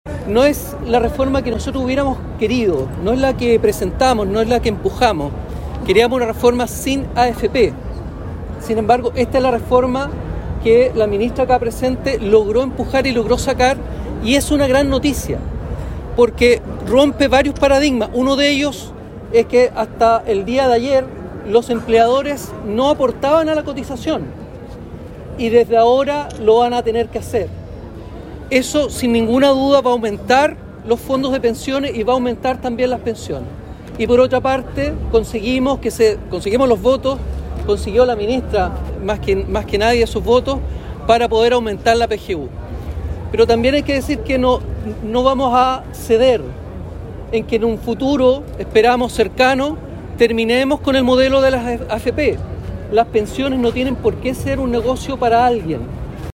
Este jueves se realizó un encuentro ciudadano en Concepción, en el contexto de la reciente promulgación de la Ley de Reforma de Pensiones, con la participación de la ministra del Trabajo y Previsión Social, Jeannette Jara, junto a otras figuras políticas de la región.